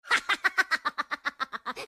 risa mi+¦on 2 malvado